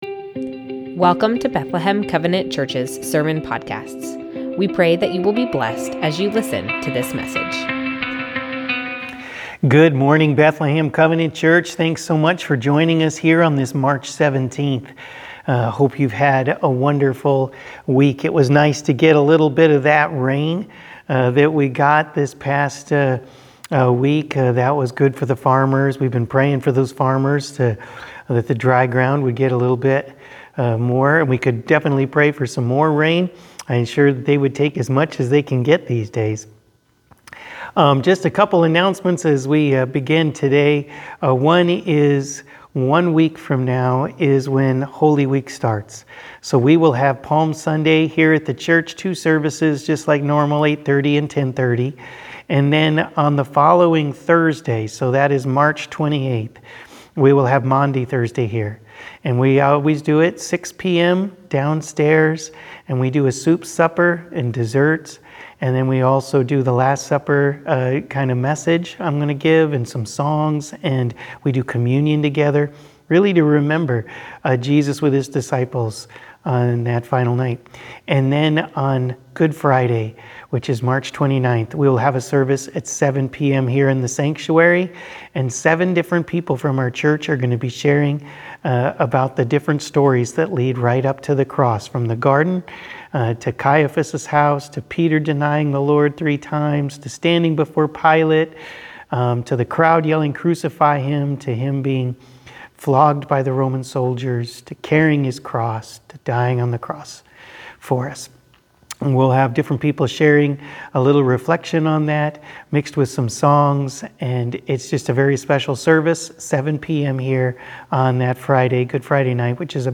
Bethlehem Covenant Church Sermons Jesus - The One who raises the Dead!